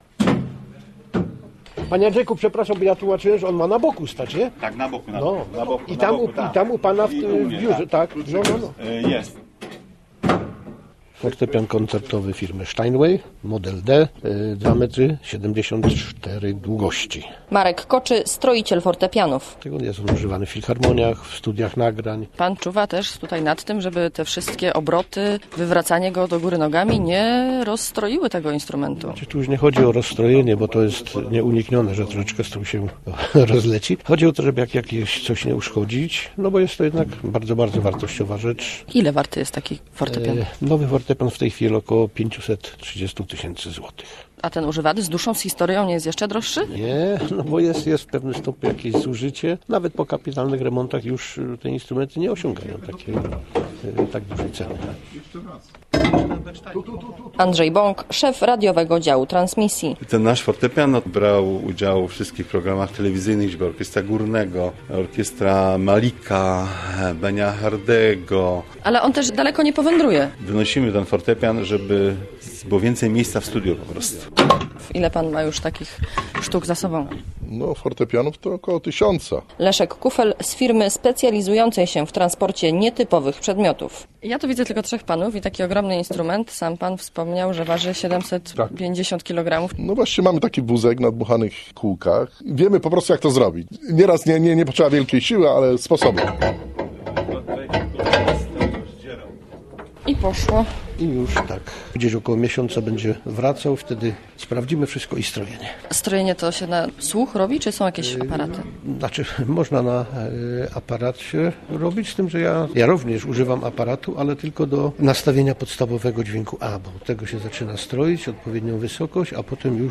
w studiu numer 1
brytyjski zespół